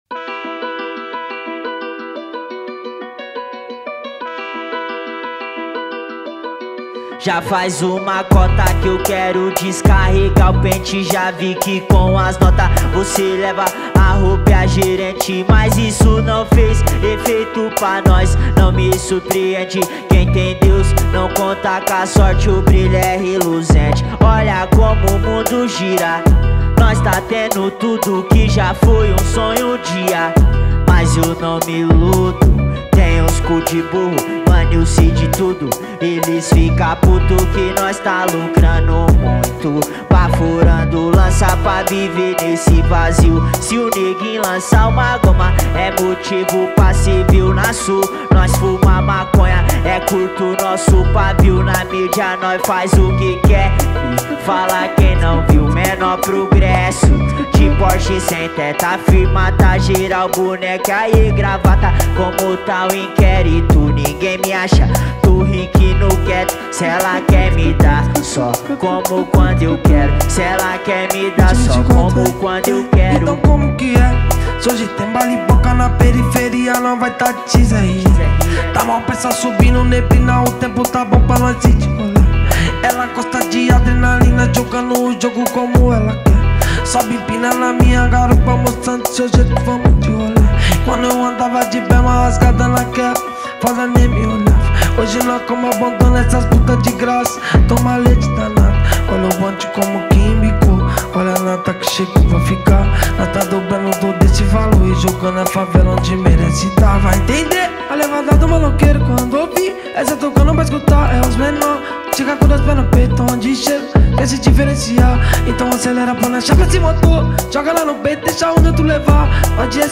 2024-10-25 22:38:02 Gênero: Funk Views